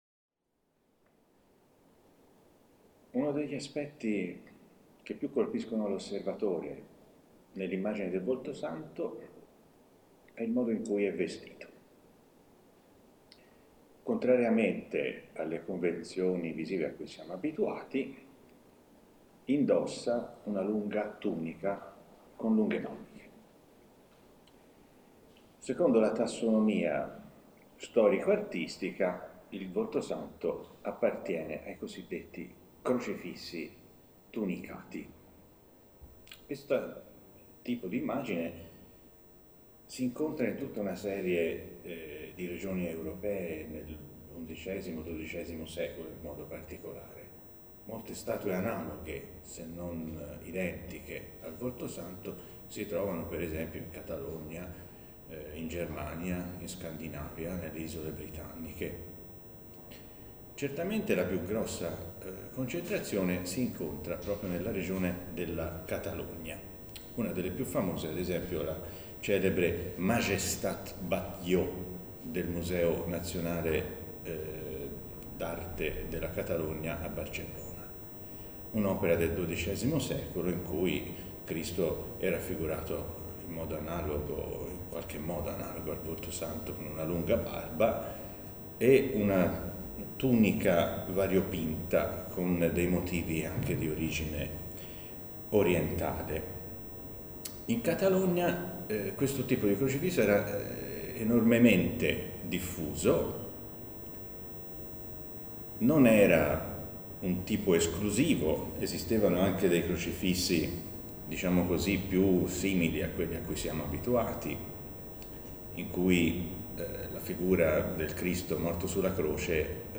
lezione